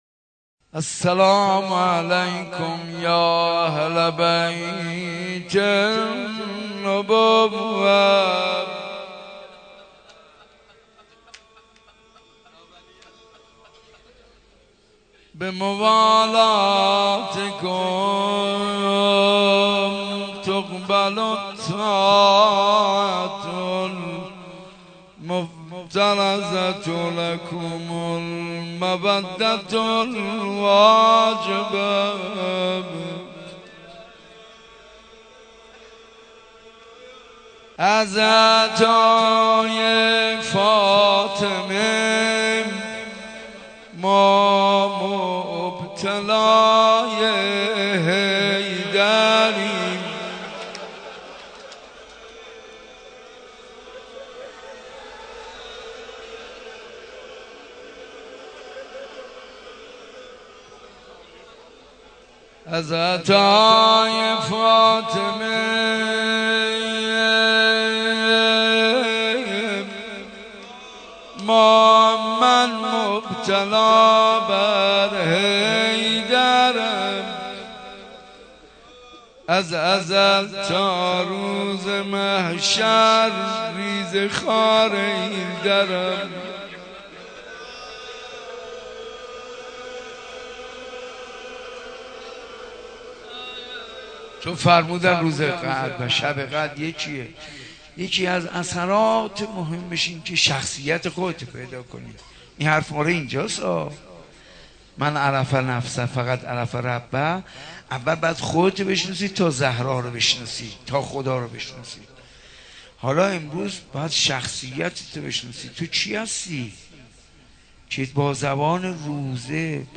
روضه حاج منصور ارضی برای حضرت امیرالمومنین(ع) - تسنیم